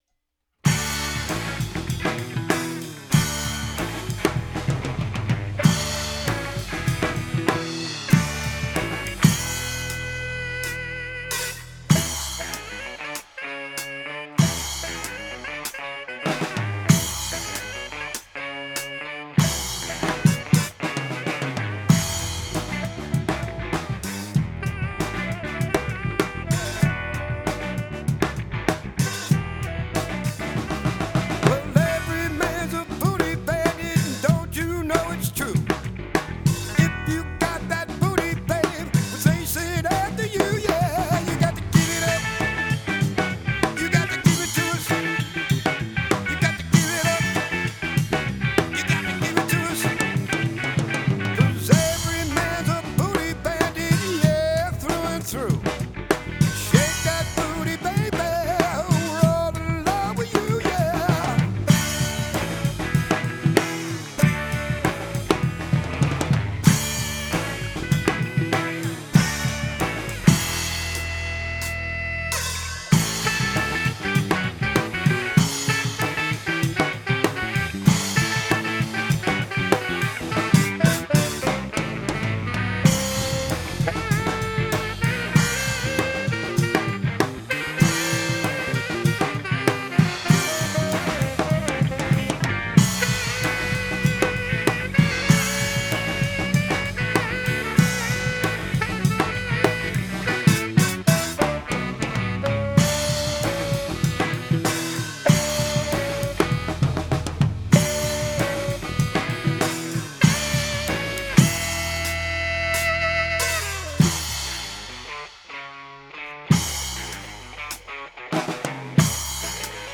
A little ditty I wrote and we recorded several years ago.
This should be the new mix with the recommended tweaking.
I EQd it and put some compression on it and brought the incoming signal into the board up.
I brightened up the drums and took the two snare tracks and panned them a bit.
Brought the sax down behind the vocals and up where needed.